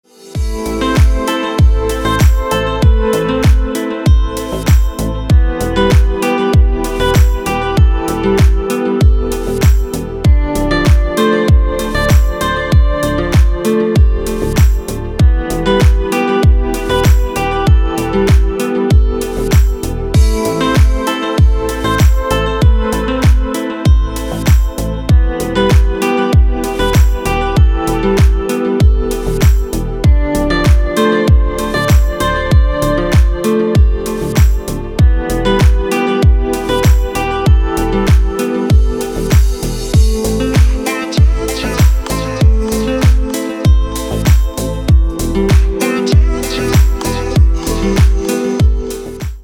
Мелодичный рингтон 2024